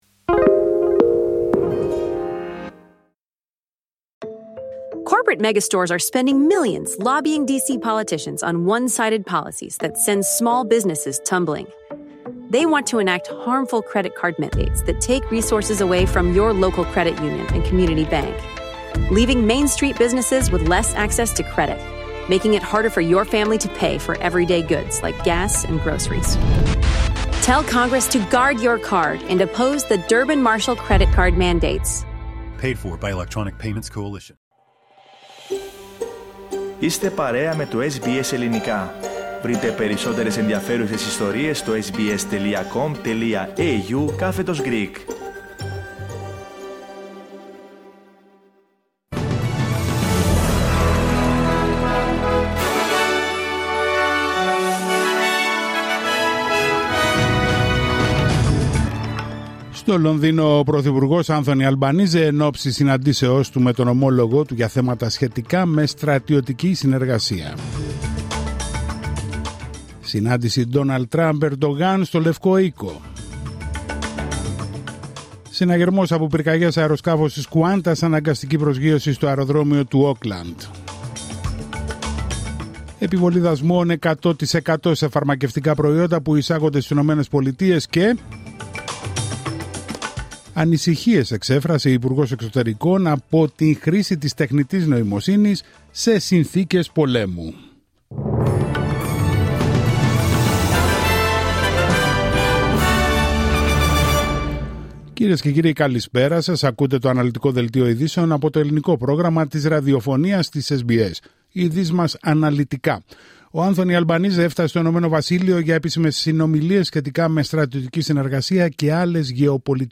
Δελτίο ειδήσεων Παρασκευή 26 Σεπτεμβρίου 2025